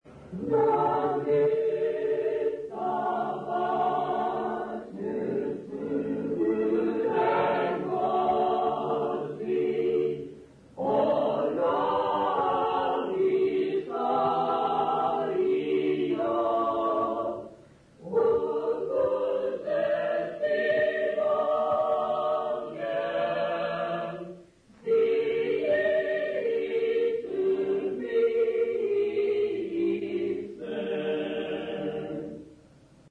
Intshanga church music workshop participants
Folk music South Africa
Hymns, Zulu South Africa
field recordings
Unaccompanied church hymn.